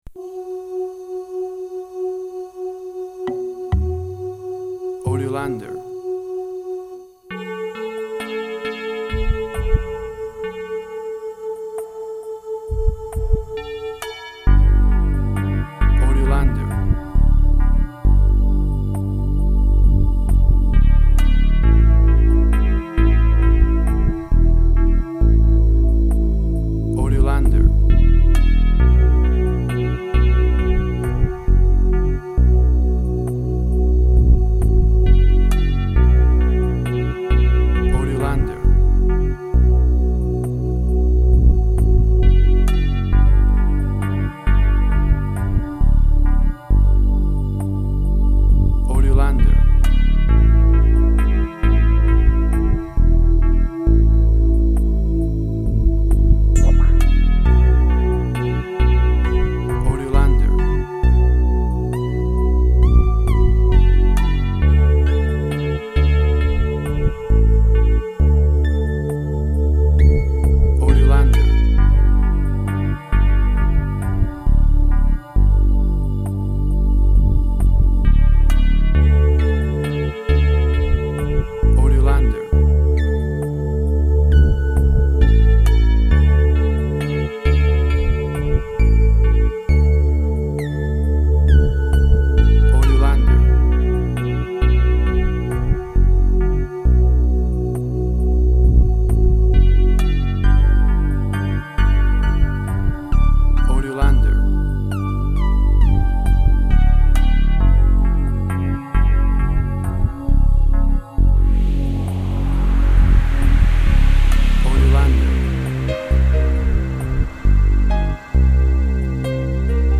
Tempo (BPM): 66